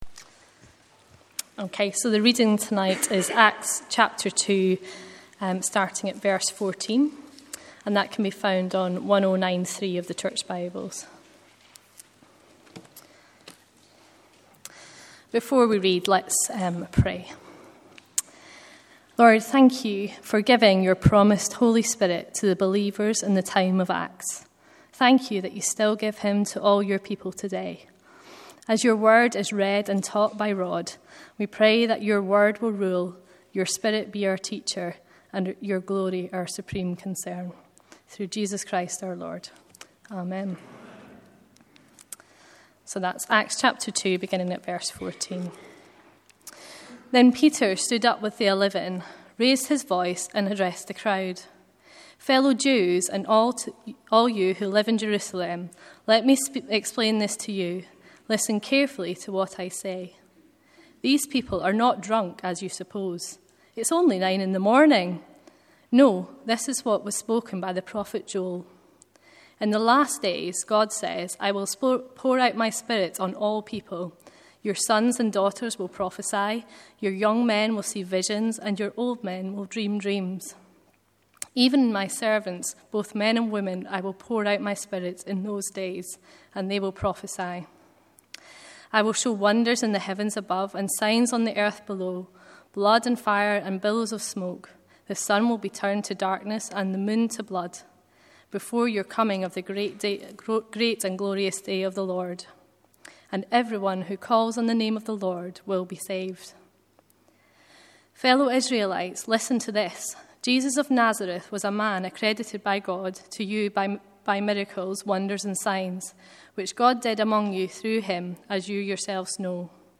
Sermons Archive - Page 72 of 188 - All Saints Preston